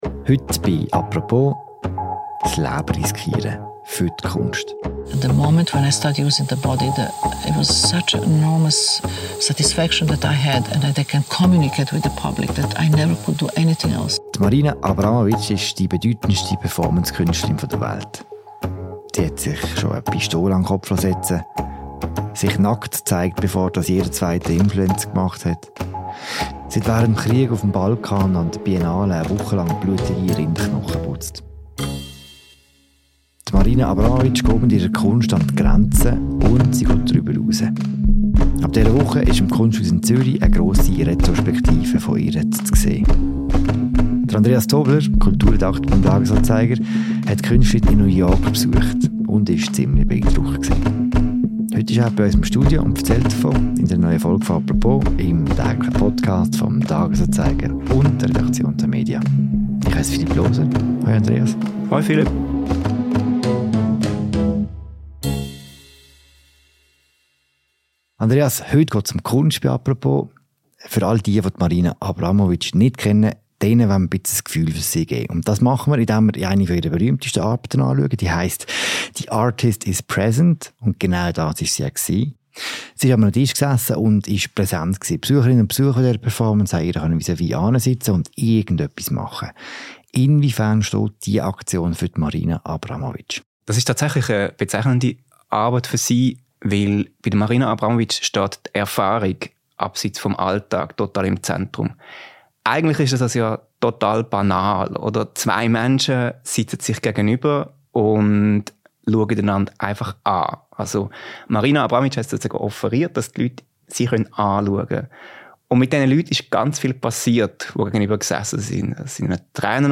Von seiner Begegnung – und überraschenden Eindrücken– erzählt er in einer neuen Folge des täglichen Podcasts «Apropos».